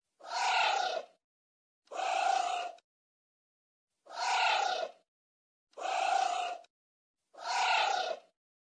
Звуки муравьеда
Муравьед встревожен